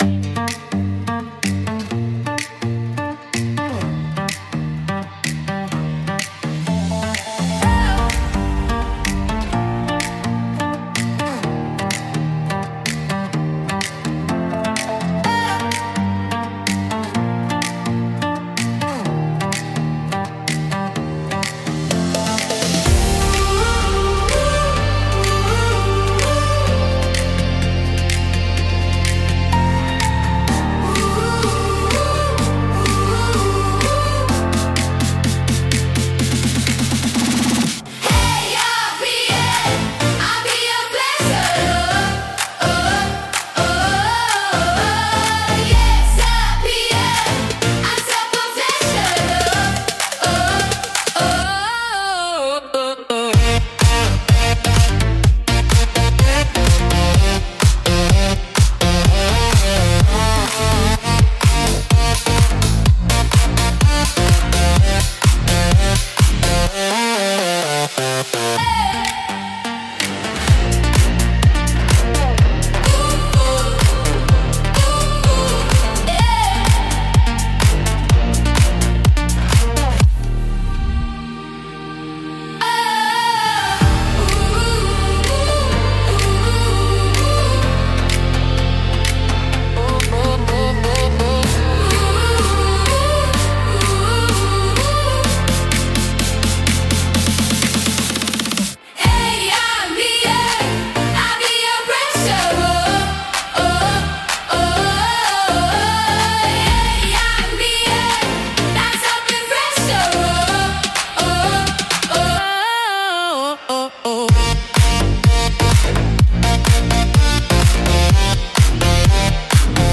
スポーツジャンルは、明るく活発な印象を持つハウス風クラブ楽曲です。
力強いリフとエネルギッシュなドラムが、スポーツやアクティビティの躍動感と熱気を力強く表現します。